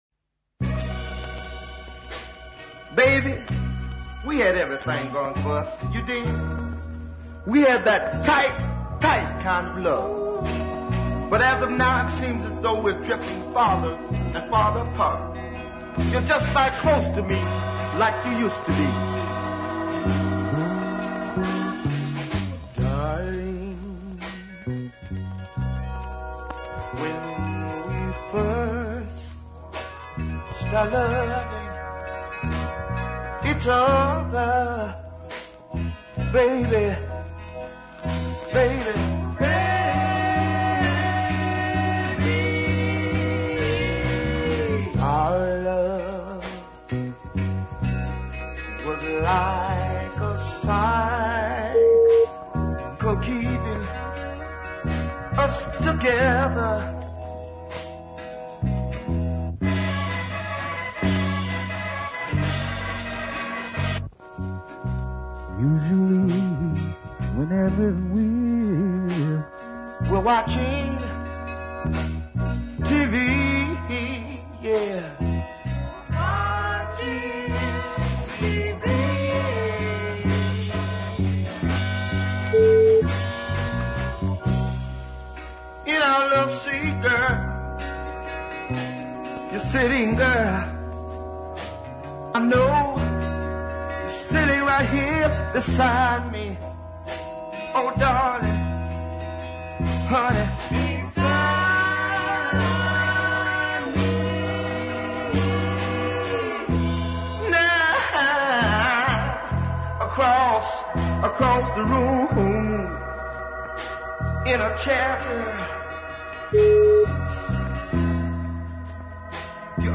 his fine gritty tone